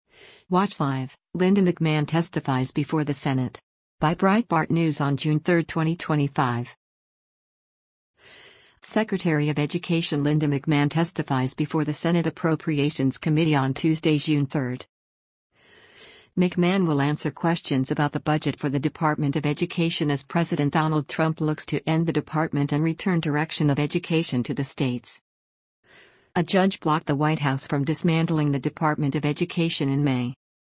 Secretary of Education Linda McMahon testifies before the Senate Appropriations Committee on Tuesday, June 3.